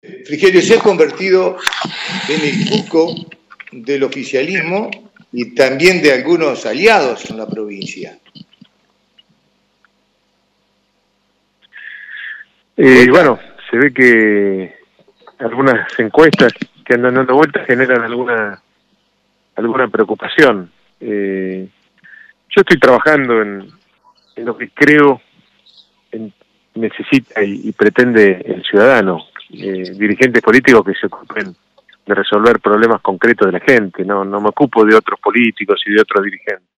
el ex ministro del Interior, Rogelio Frigerio, habló de la actualidad provincial:
Declaraciones-de-Rogelio-Frigerio-1.mp3